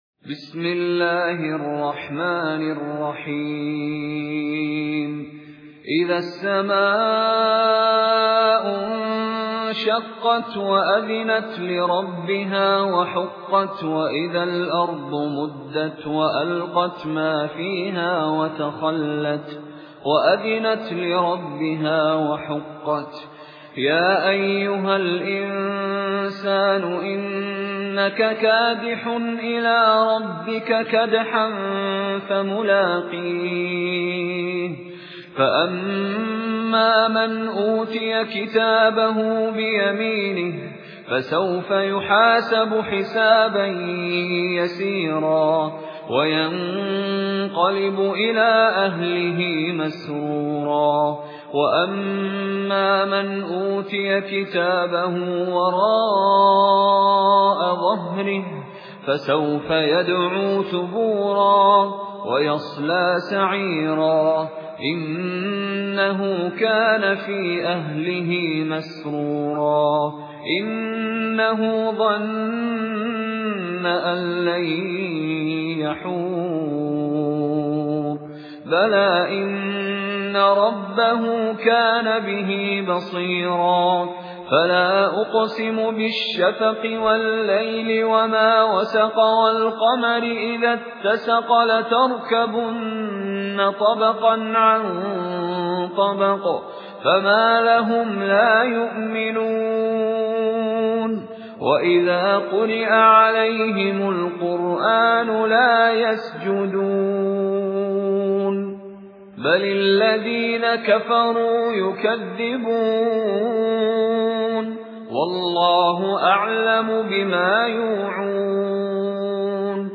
مشاري راشد العفاسي ( قصر المنفصل من طريق الطيبة )